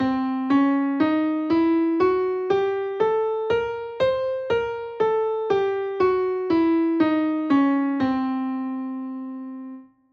A symmetrical diminished scale consists of eight notes, thus making it an octatonic scale.
This scale is widely used in world music and has a certain Eastern flavour to it.
Symmetrical diminished scale audio example:
Audio: Jazz Symmetrical Scales Octa Scale
Jazz-Symmetrical-Scales-Octa-Scale.mp3